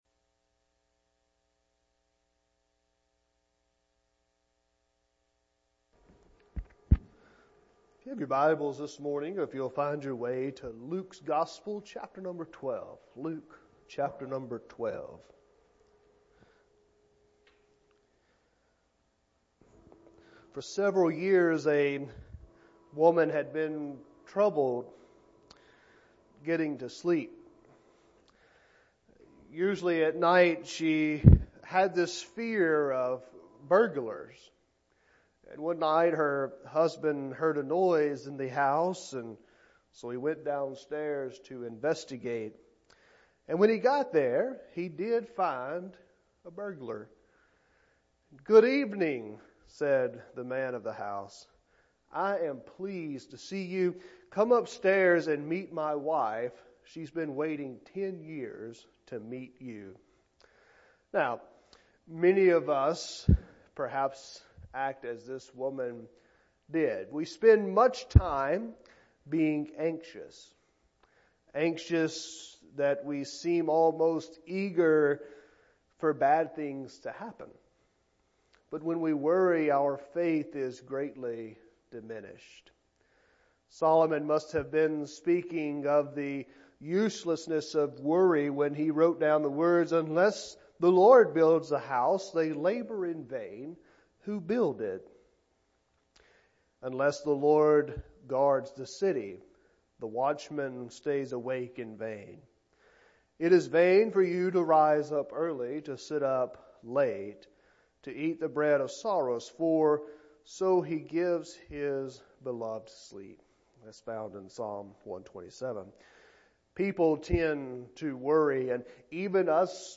Sermons | West Acres Baptist Church